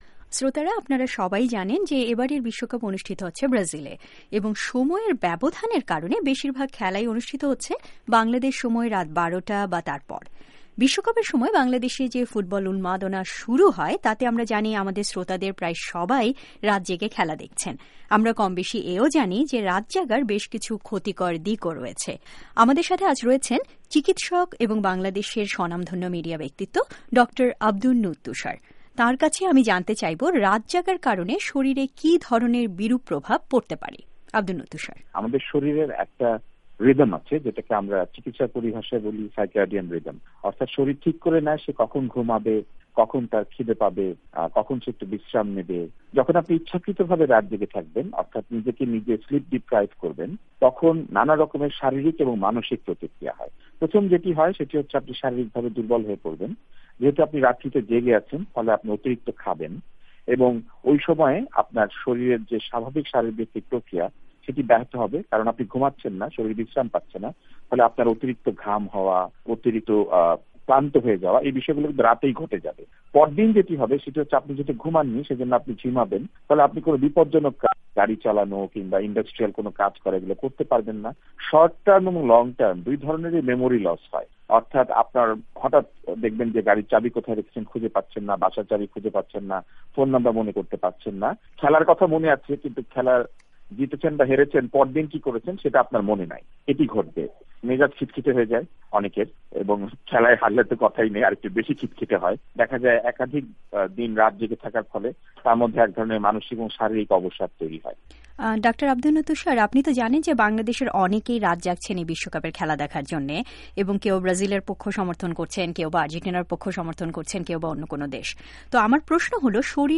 রাত জাগার কুফলঃ ডাঃ আব্দুন নূর তুষারের সাক্ষাতকার
Dr. Tushar Interview